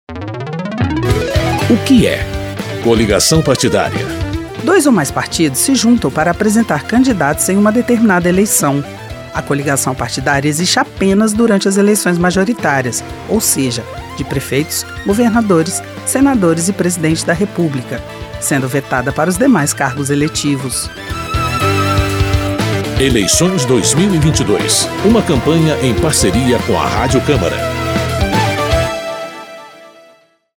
São 13 spots de 30 segundos.